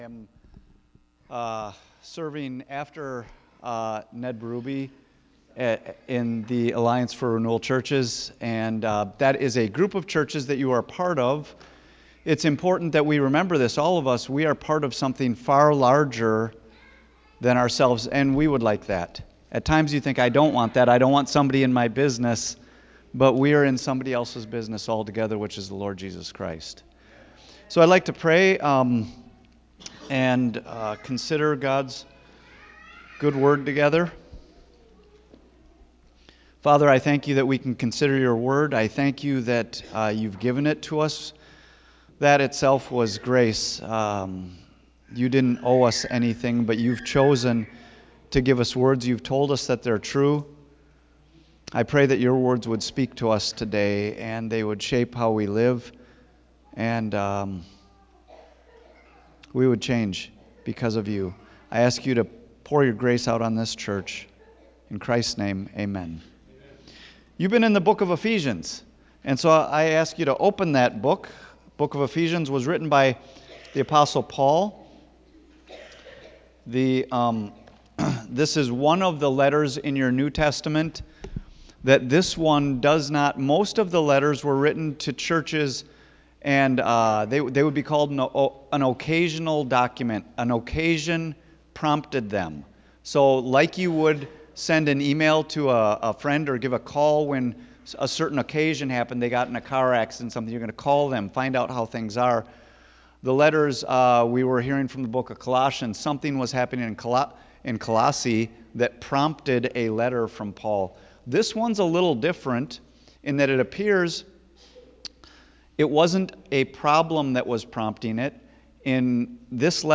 October 15 Sermon | A People For God